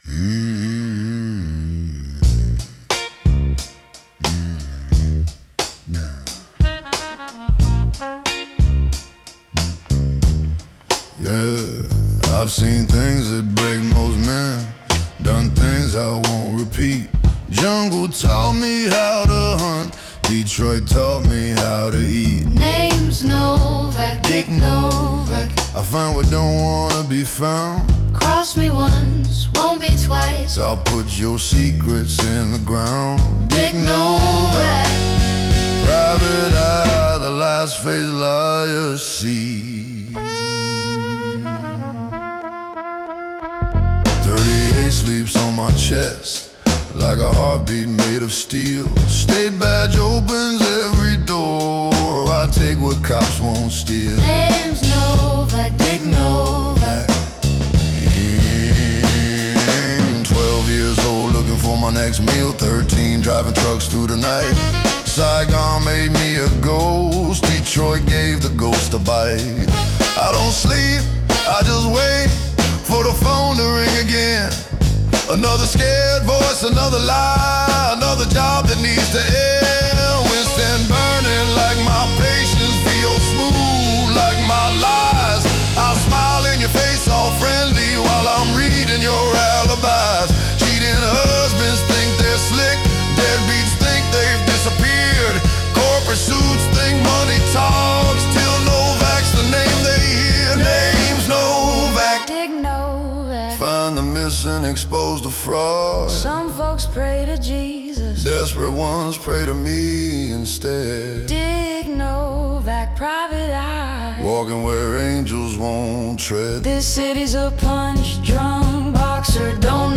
Baritone Vocals
Piano
Saxophone
Guitar
Drums
Upright Bass
Backing Vocals
Main Theme